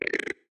1.21.5 / assets / minecraft / sounds / mob / strider / idle1.ogg